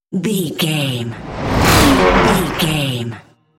Sci fi whoosh electronic flashback
Sound Effects
Atonal
futuristic
tension
whoosh